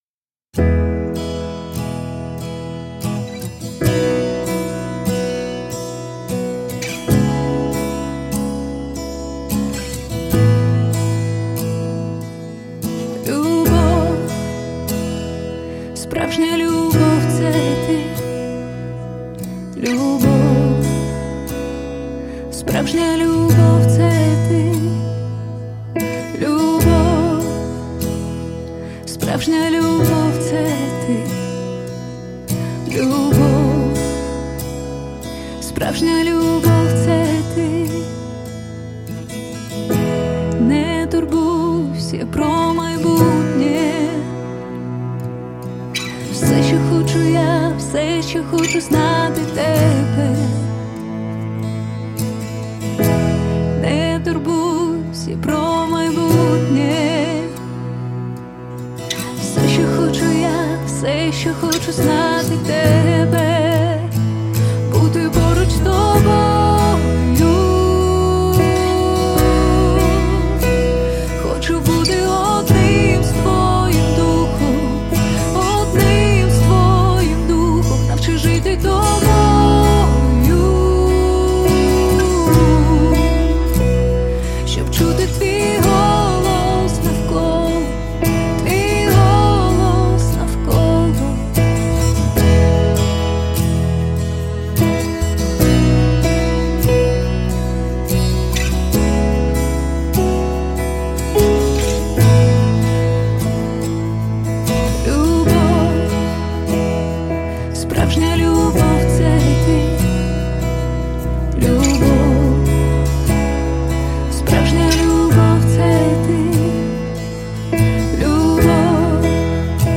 472 просмотра 17 прослушиваний 2 скачивания BPM: 70